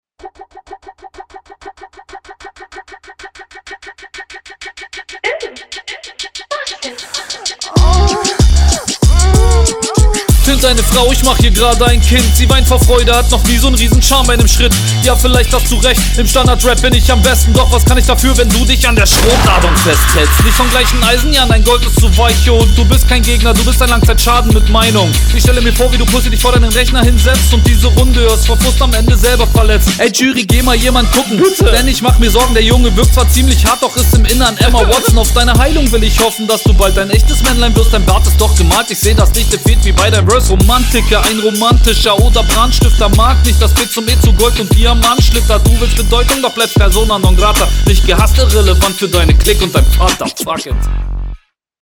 Er hat mich Flowlich überrascht war besser als ich erwartet habe.
Flow ok, Mix ausbaufähig, Punches eher weak, Reime gehen klar